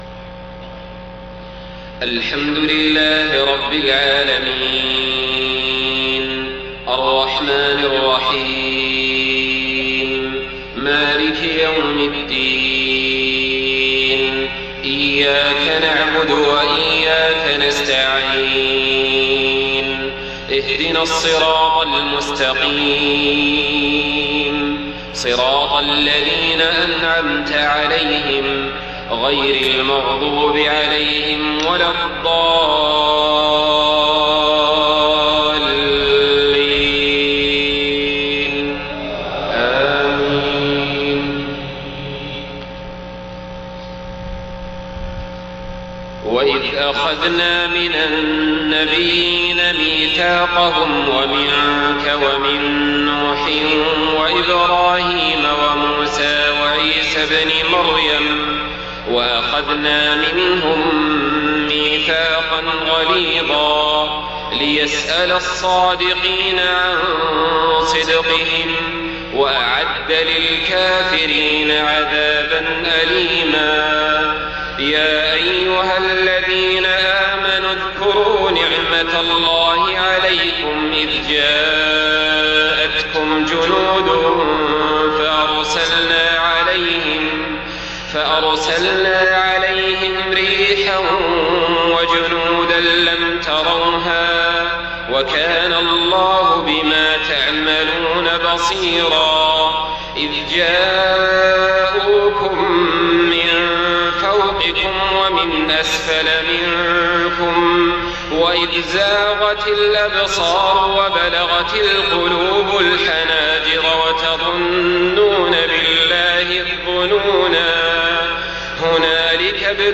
صلاة العشاء 7 محرم 1430هـ من سورة الأحزاب 7-18 و 21-27 > 1430 🕋 > الفروض - تلاوات الحرمين